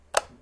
switch21.ogg